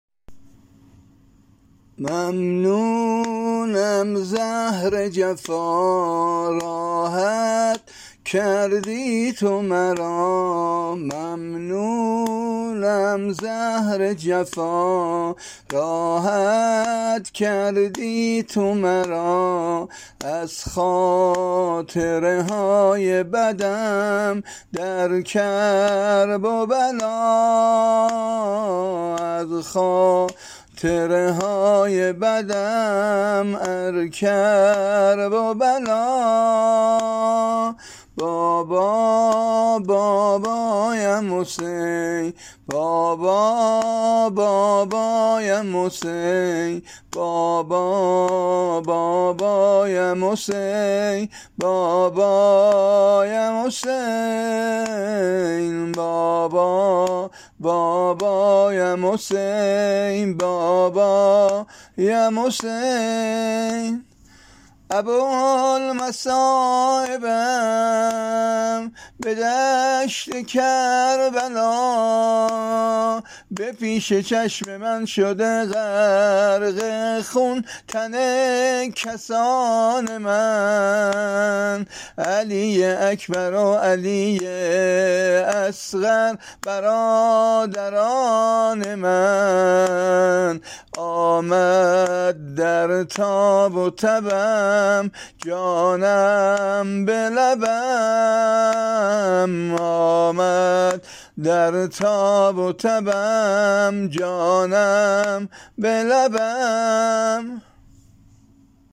زمزمه ونوحه امام سجاد